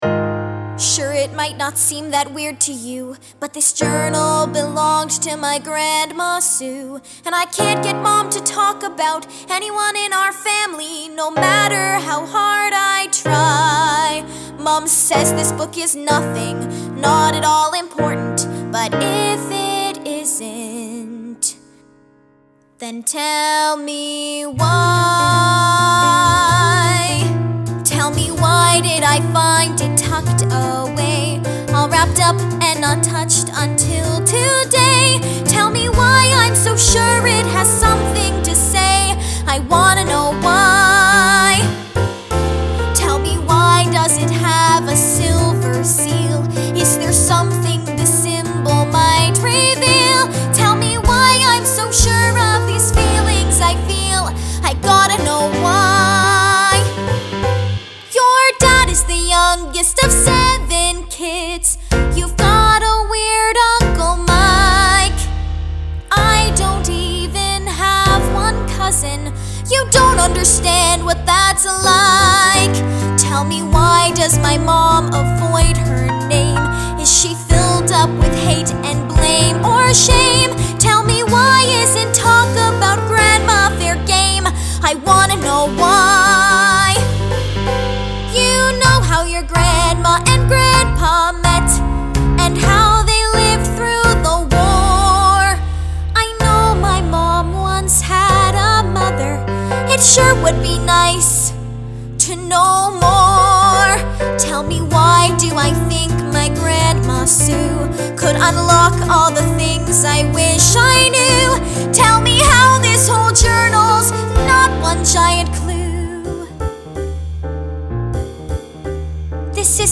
(Bb3-C5)
Vocal Demo